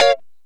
Lng Gtr Chik Min 06-A#2.wav